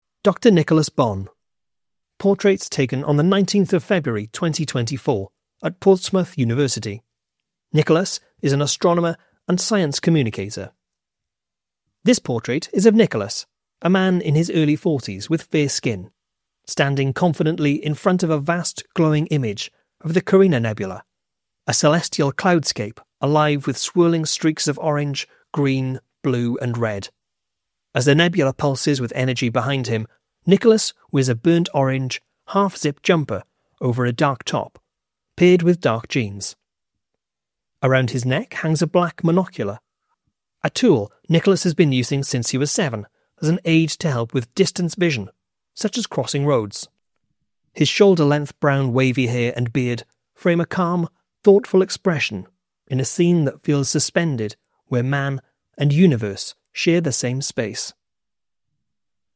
Seen Exhibition Audio Description